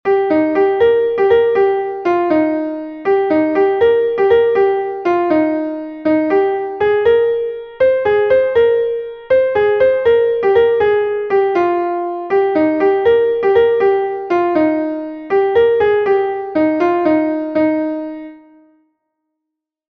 Ton Bale Langedig est un Bale de Bretagne